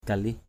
/ka-lɪh/